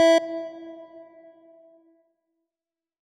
E1.wav